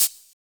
Closed Hats
edm-hihat-51.wav